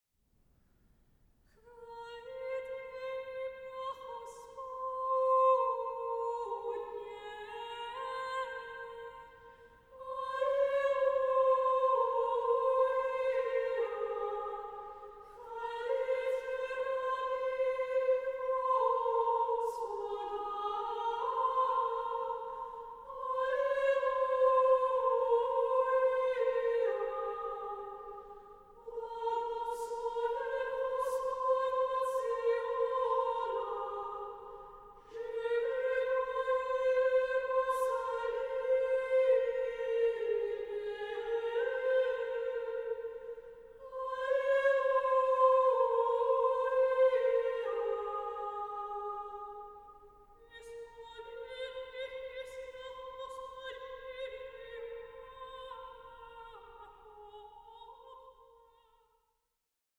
choral masterpiece